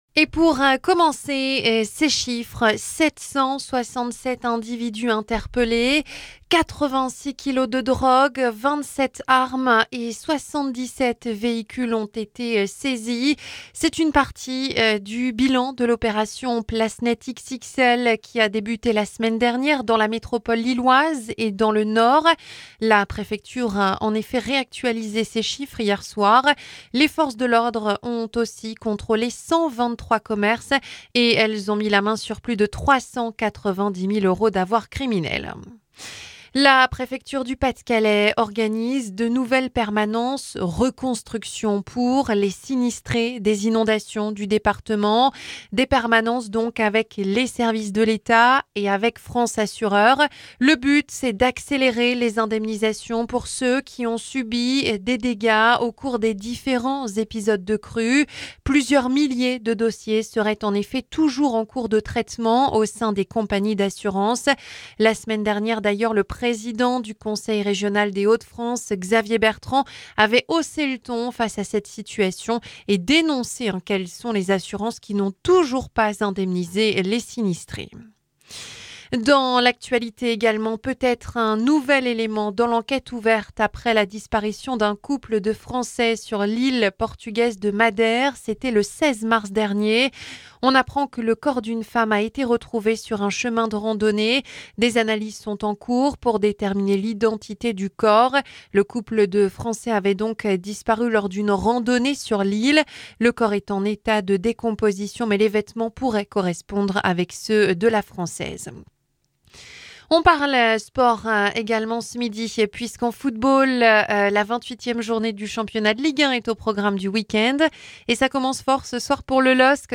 Journal 12h - 767 individus interpellés et 86kg de drogue saisis depuis la semaine dernière dans la métropole lilloise et le Nord